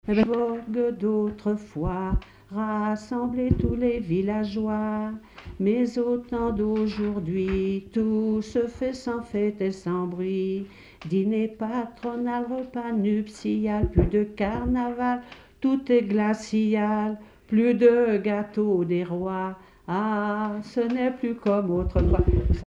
Saint-Julien-en-Genevois
Pièce musicale inédite